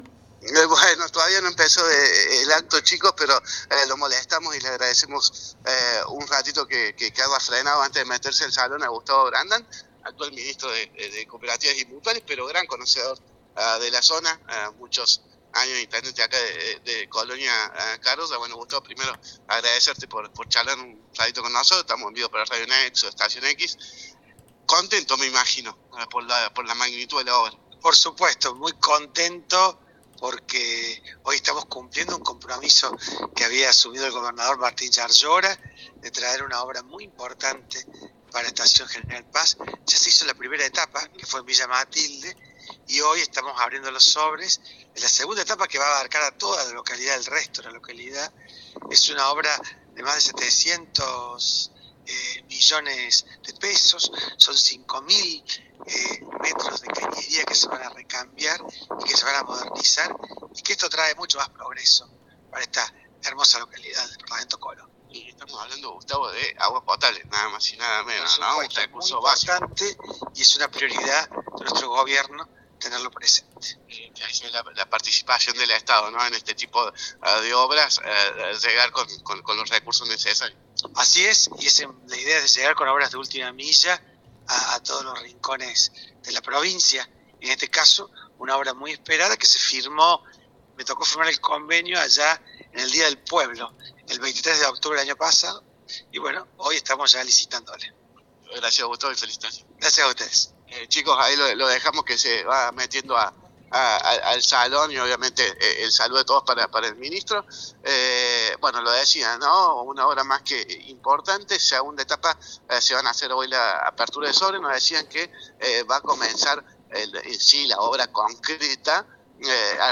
En la previa, el móvil de Nexo FM entrevistó a Gustavo Brandan, ministro de Cooperativas y Mutuales de la provincia de Córdoba, quien se expresó “muy contento, porque estamos cumpliendo un compromiso que había asumido el gobernador Llaryora de traer una obra muy importante”.
ENTREVISTA A GUSTAVO BRANDAN, MINISTRO DE COOPERATIVAS Y MUTUALES DE CÓRDOBA